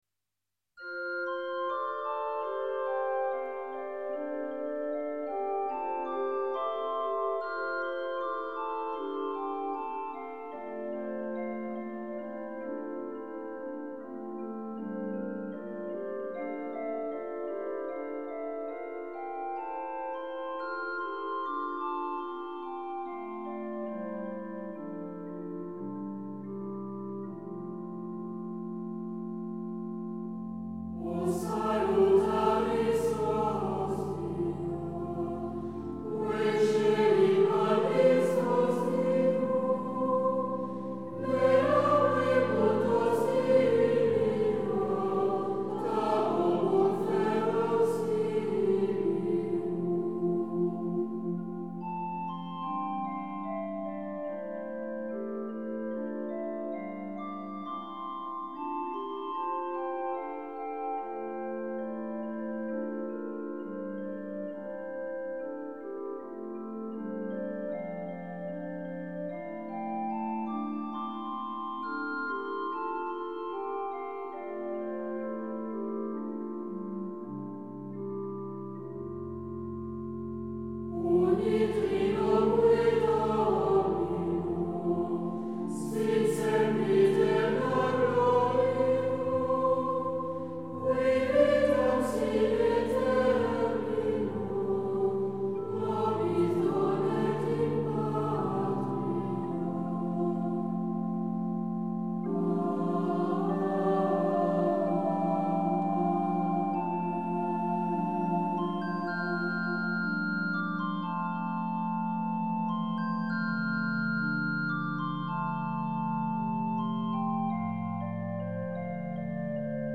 CHANTS GRÉGORIENS